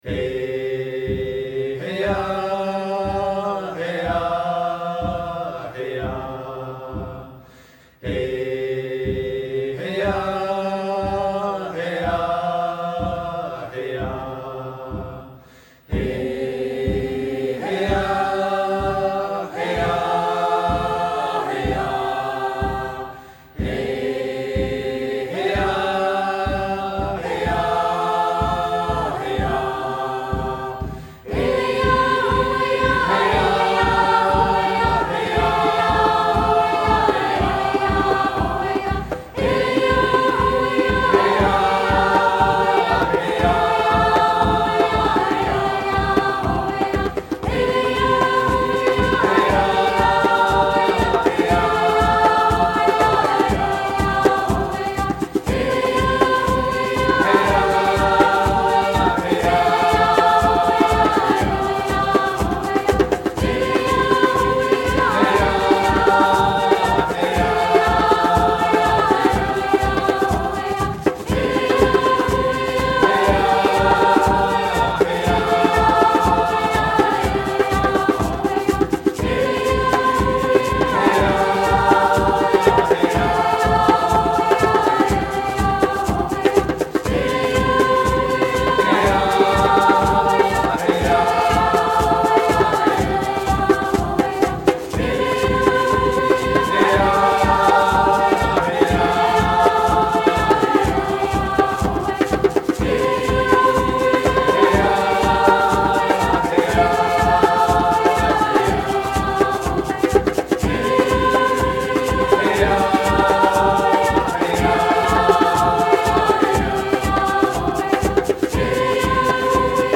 Welcome Chant